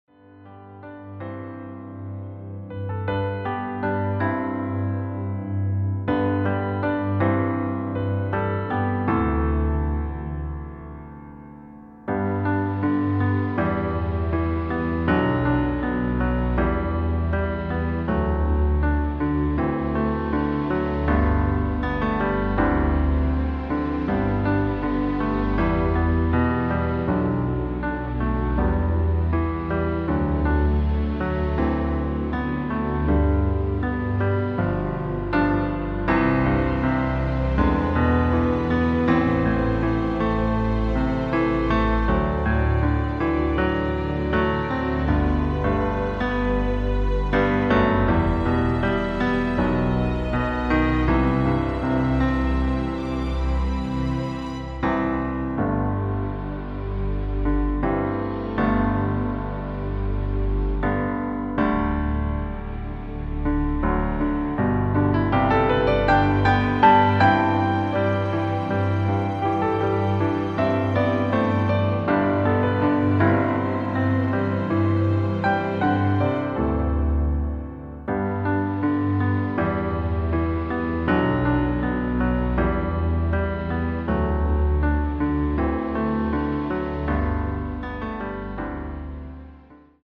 Klavierversion
• Tonart: A Dur, Bb Dur & G Dur
• Art: Klavier Streicher
• Das Instrumental beinhaltet NICHT die Leadstimme
Klavier / Streicher